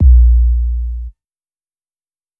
KICK_FINALLY.wav